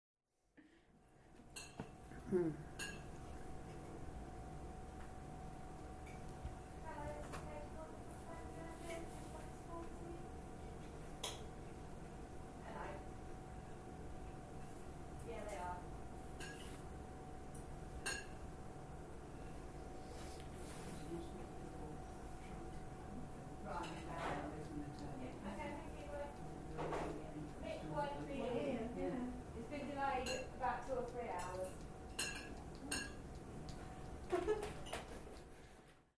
Lunch time at the Fenland Airfield Club House